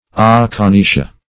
aconitia - definition of aconitia - synonyms, pronunciation, spelling from Free Dictionary Search Result for " aconitia" : The Collaborative International Dictionary of English v.0.48: Aconitia \Ac`o*ni"ti*a\, n. (Chem.)